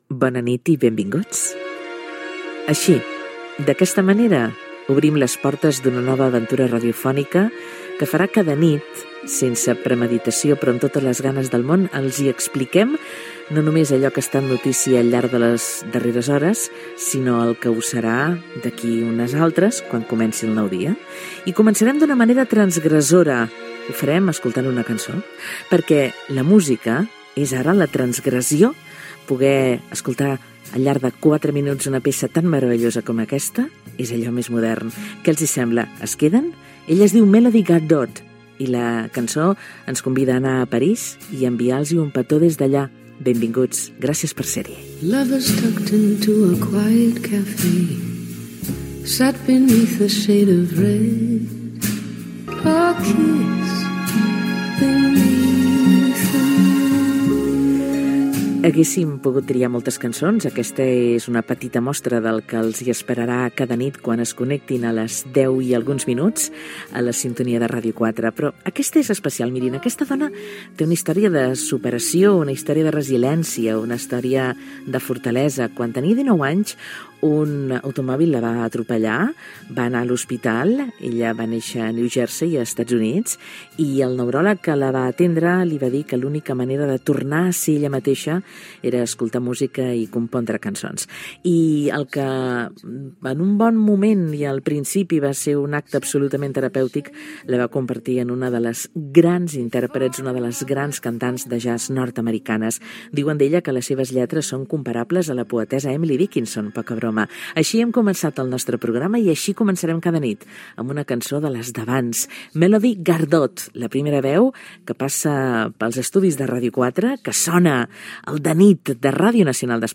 Presentació de la primera edició del programa, tema musical, dades de la cantant Melody Gardot, indicatiu del programa, equip, "La notíca" sobre la desqualificació d'una atleta invident als Jocs Palímpics de París Gènere radiofònic Entreteniment